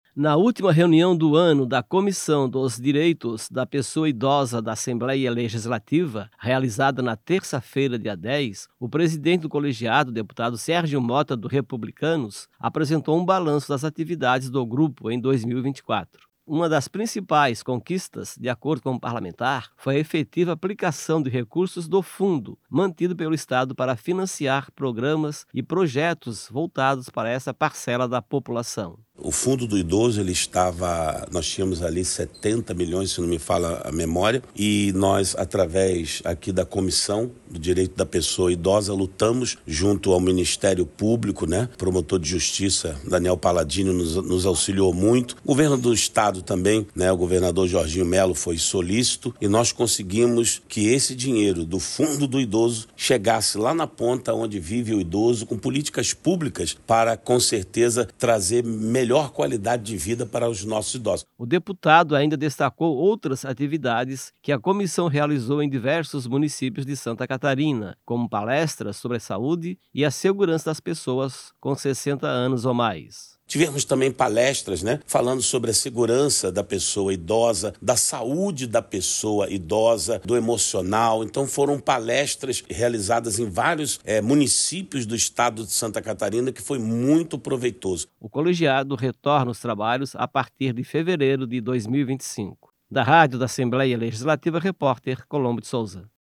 Entravistas com:
- Deputado Sérgio Motta (Republicanos).